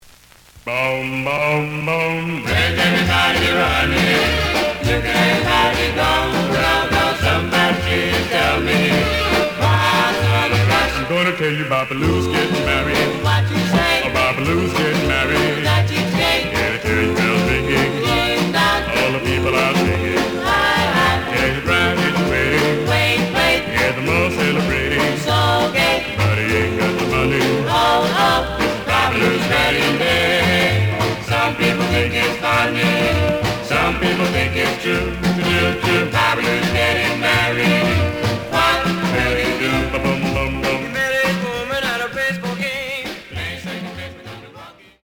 ●Genre: Rhythm And Blues / Rock 'n' Roll
Some click noise on both sides.